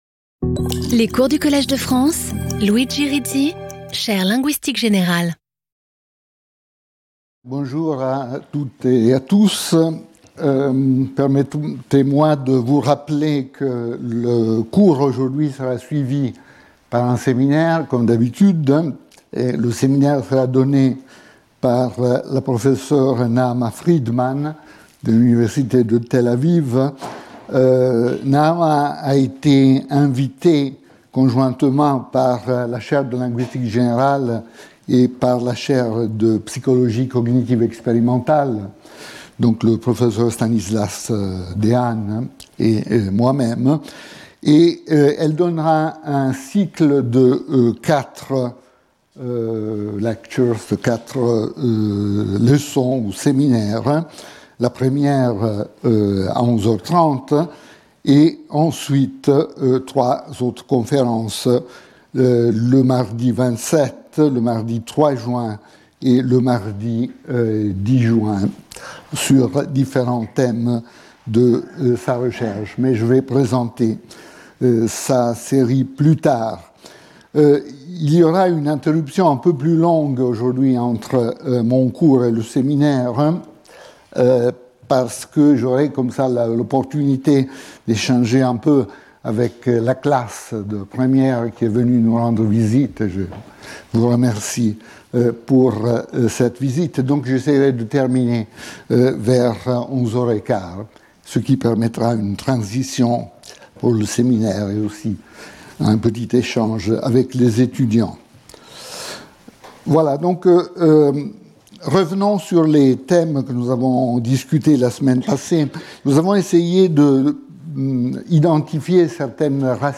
Luigi Rizzi Professeur du Collège de France
Cours